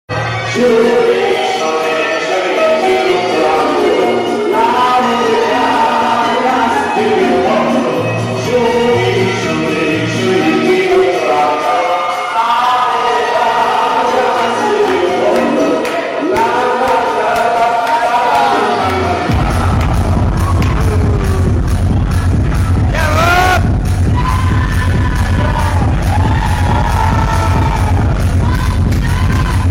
special edit (unreleased)
dj set